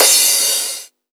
VMH1 Crash
VMH1 Crash 01.wav